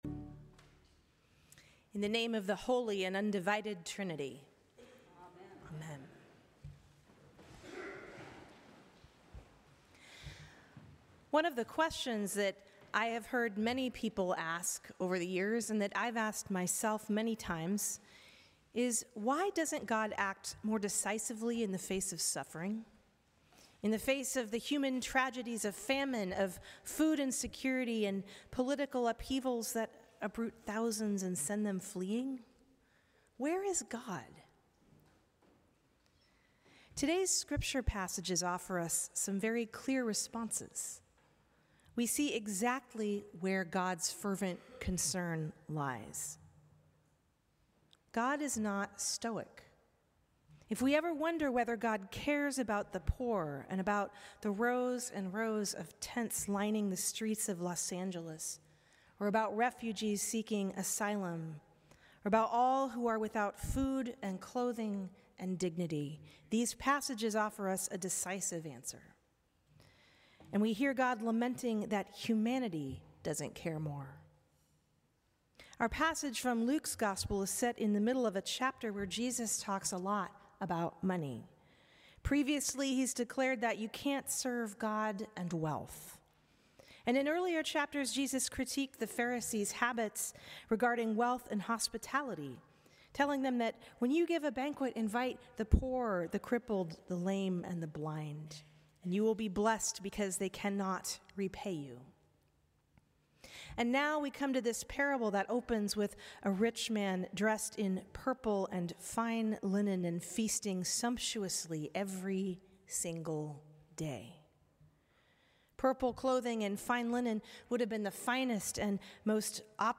Sermons
St. Cross Episcopal Church